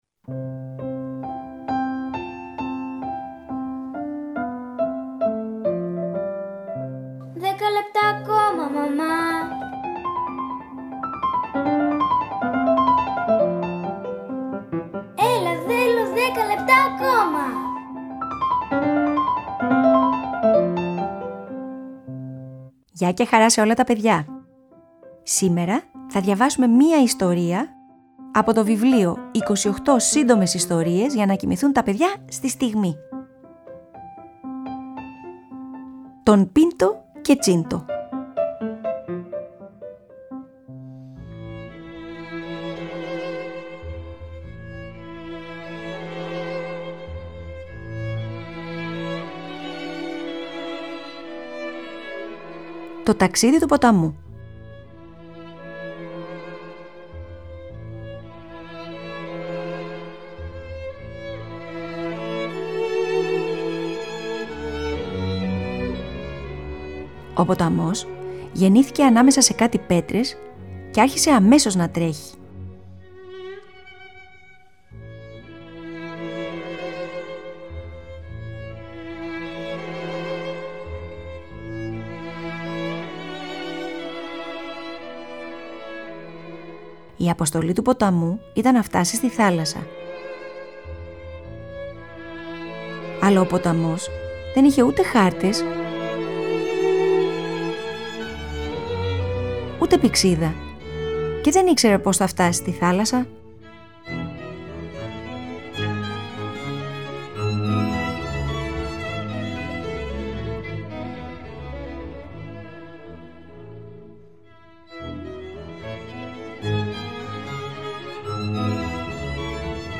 Antonin Dvorak, Two Waltzes, Op.54. 105 (for string quartet)in D&A Major